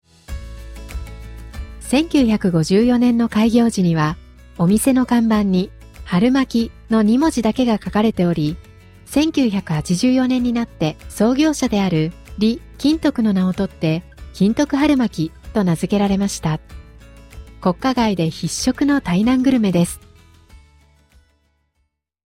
日本語音声ガイド